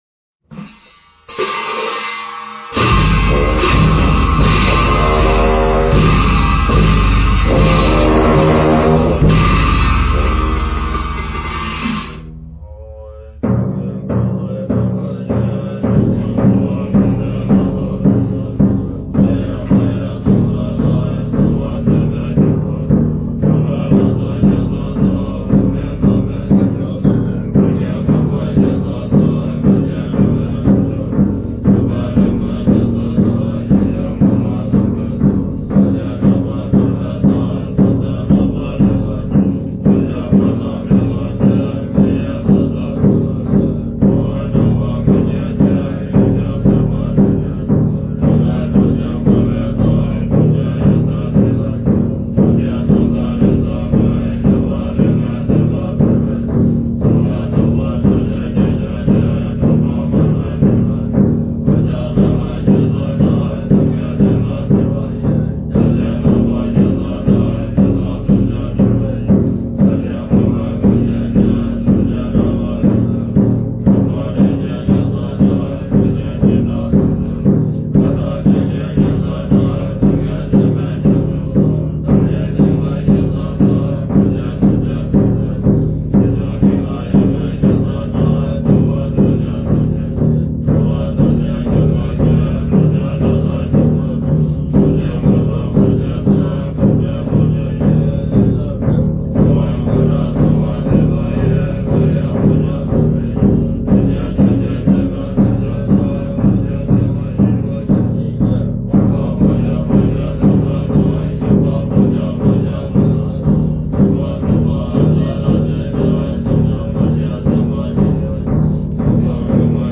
佛音 诵经 佛教音乐 返回列表 上一篇： 峨嵋佛光 下一篇： 忿怒莲师冈梭(二